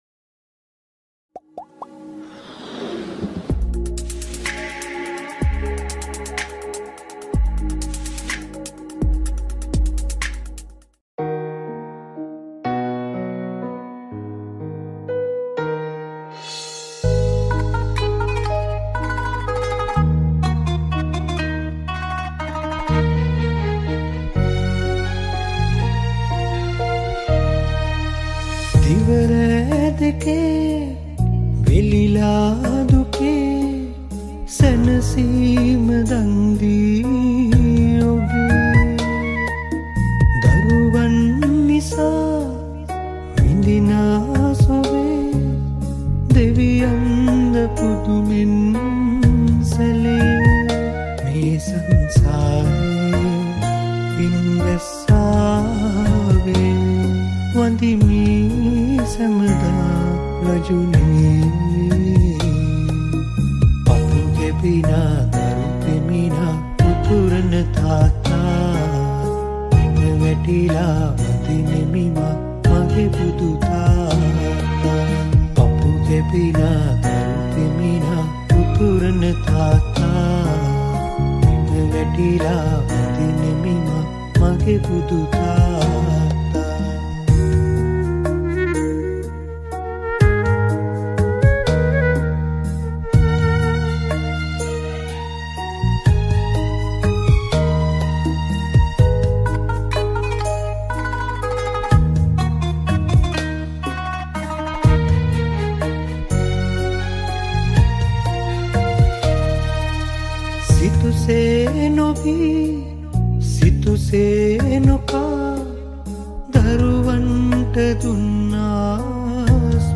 High quality Sri Lankan remix MP3 (3.2).